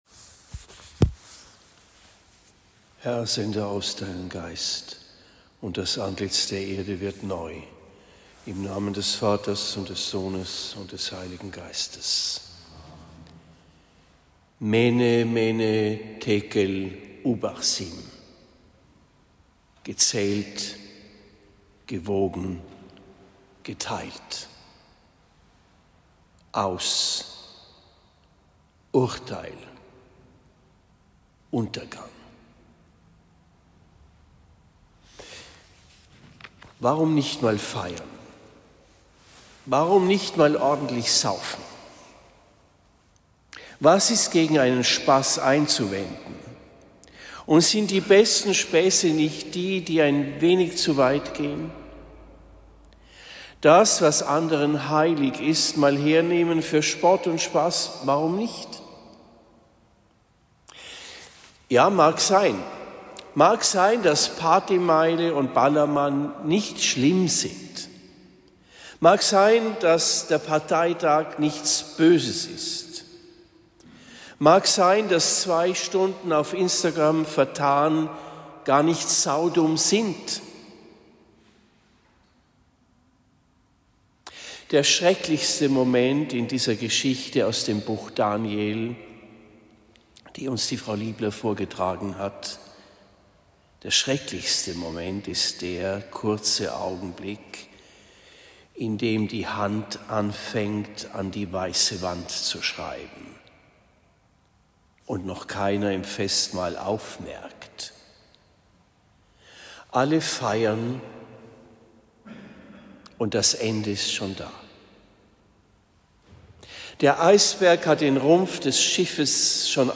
Predigt in Marktheidenfeld St.-Laurentius am 26. November 2025